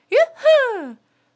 a_cheer3.wav